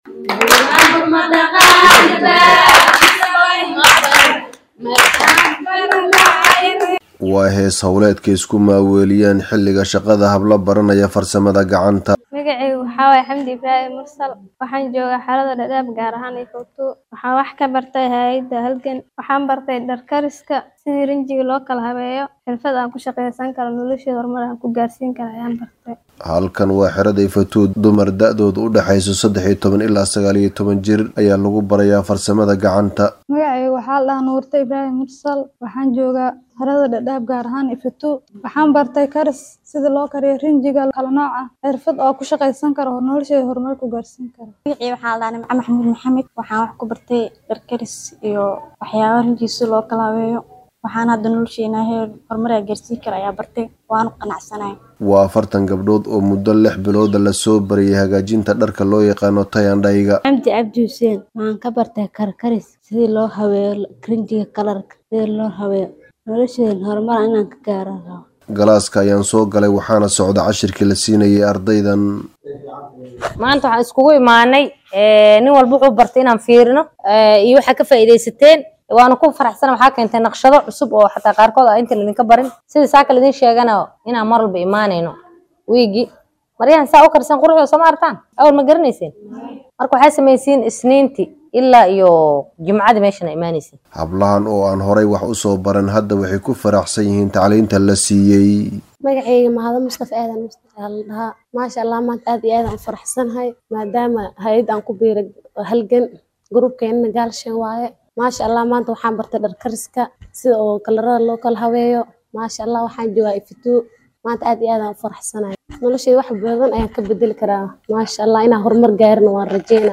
warbixintan ayuuna ka soo diray Dadaab